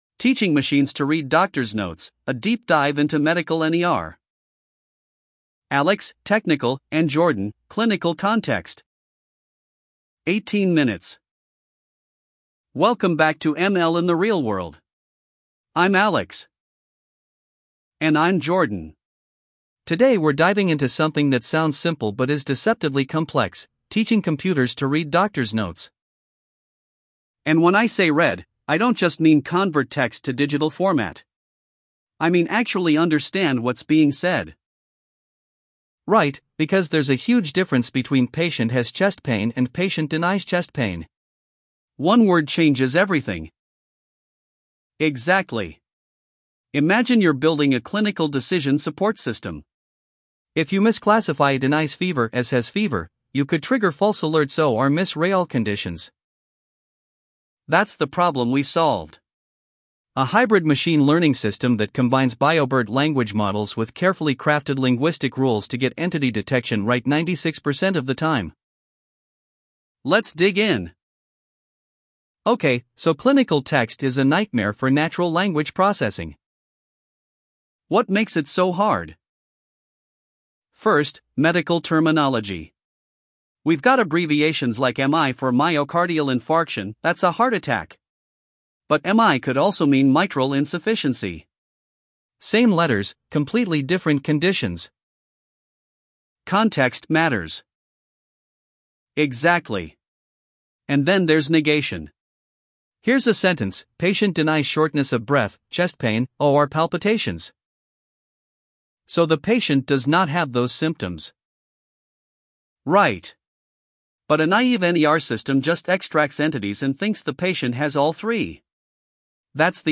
9 minute audio companion • Two-host conversational format